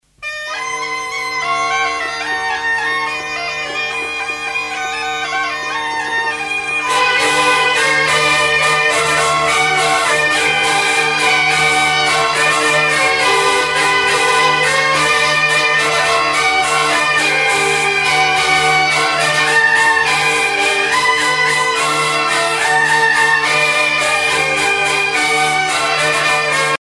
danse : bourree